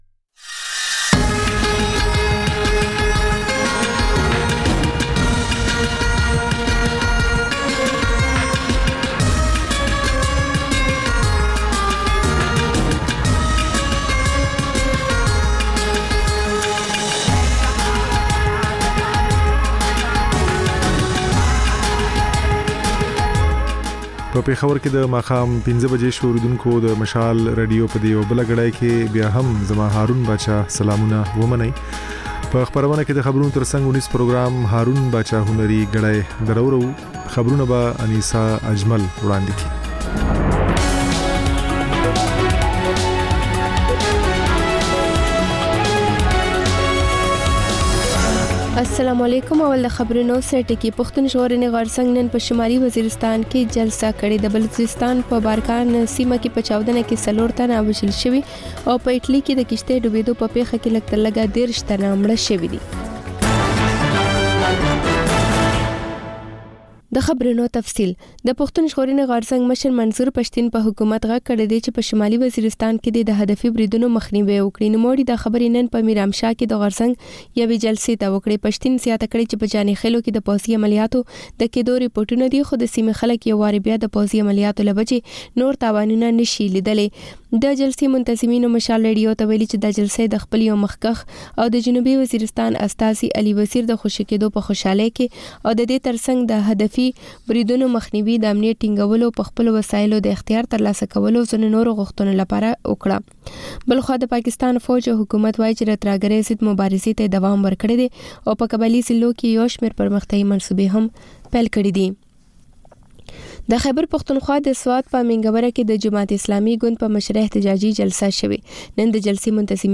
د مشال راډیو ماښامنۍ خپرونه. د خپرونې پیل له خبرونو کېږي، بیا ورپسې رپورټونه خپرېږي.
ځېنې ورځې دا مازیګرنۍ خپرونه مو یوې ژوندۍ اوونیزې خپرونې ته ځانګړې کړې وي چې تر خبرونو سمدستي وروسته خپرېږي.